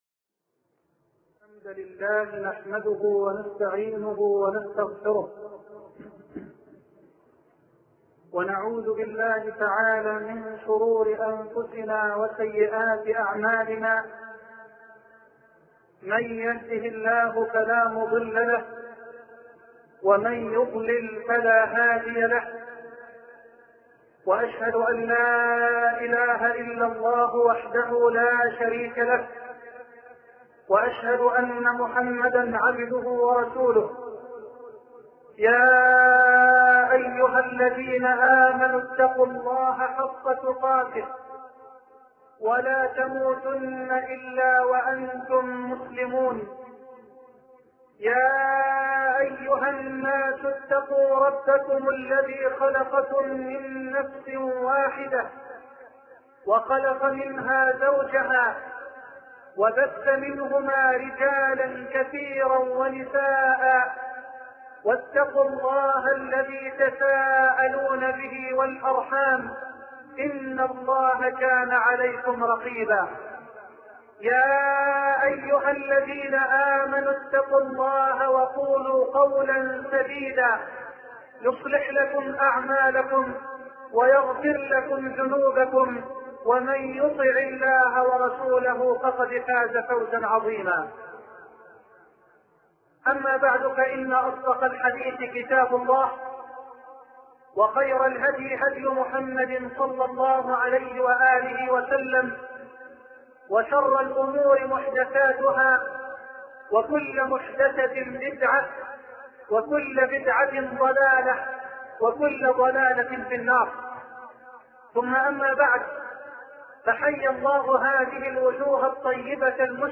شبكة المعرفة الإسلامية | الدروس | أصول التربية [1] |محمد حسان